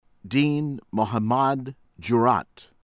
JOYA, MALALAI mah-lah-LAY    JOY-ah